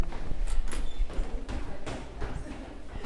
描述：现场录制重，肥雨，风力不大。三声或三声以上真正大声遥远的雷击和滚雷声。路易斯安那州
Tag: 雷暴 环境 风暴 脂肪 暴雨 天气 隆隆声 闪电 滚动雷 自然 淋浴 现场记录 下雨